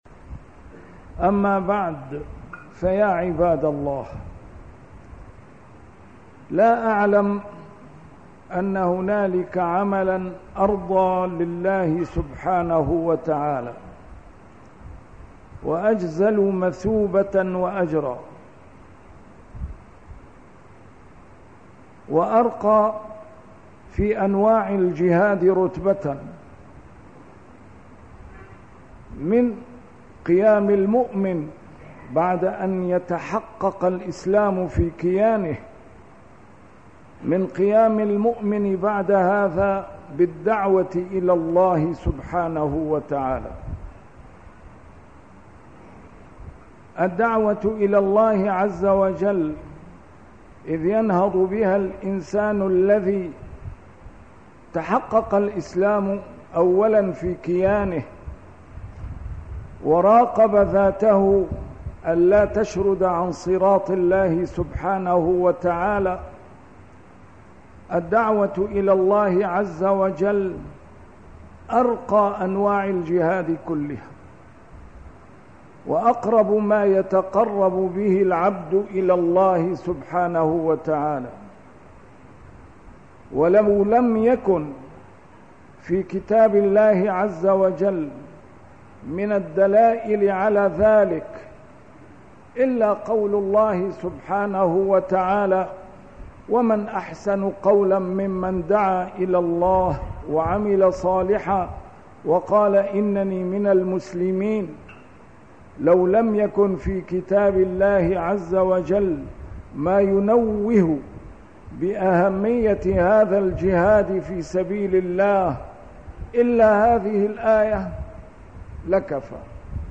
A MARTYR SCHOLAR: IMAM MUHAMMAD SAEED RAMADAN AL-BOUTI - الخطب - أين هم الدعاة إلى الله؟!